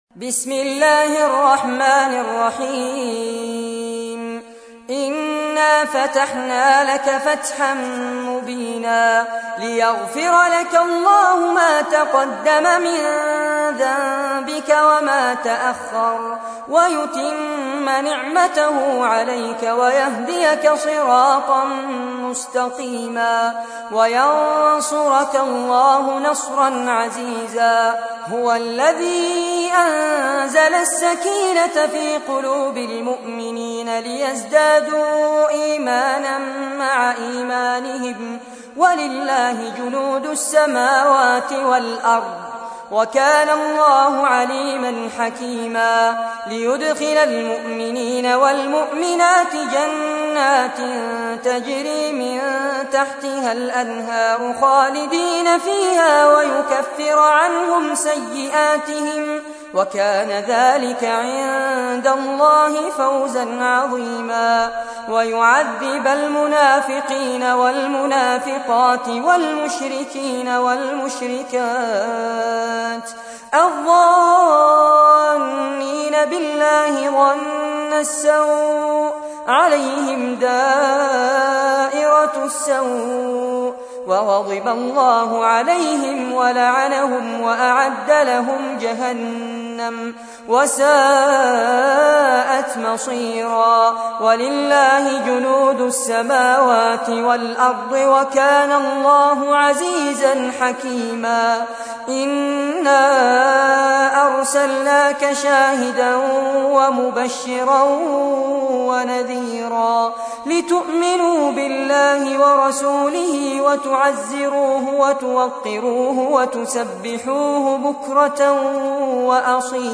سورة الفتح / القارئ فارس عباد / القرآن الكريم / موقع يا حسين